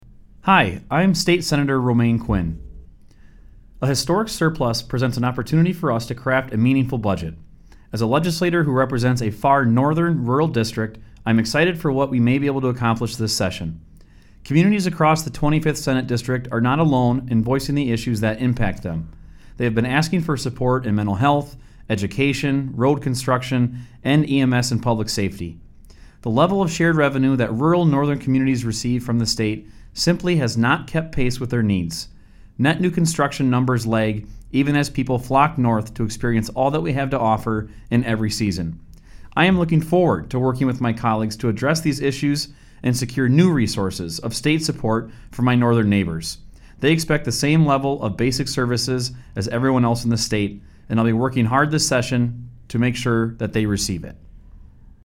Weekly GOP radio address: Sen. Quinn pledges to support priorities for rural Wisconsinites - WisPolitics